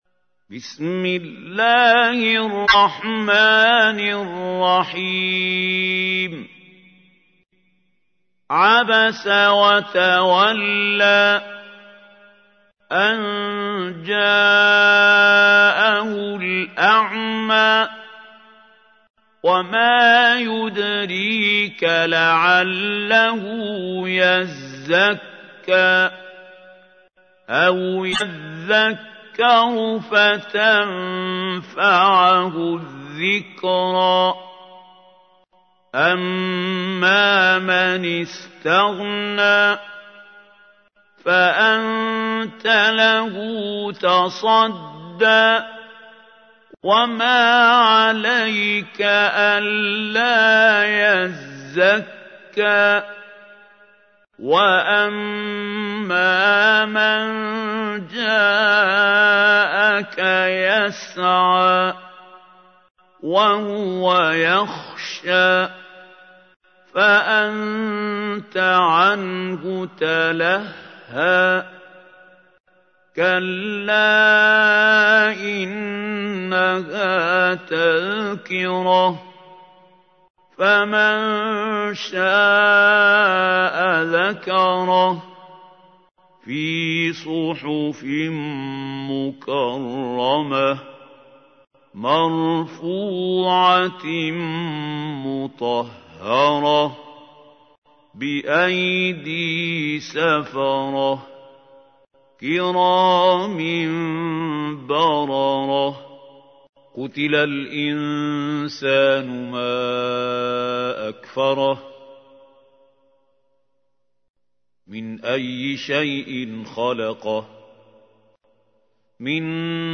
تحميل : 80. سورة عبس / القارئ محمود خليل الحصري / القرآن الكريم / موقع يا حسين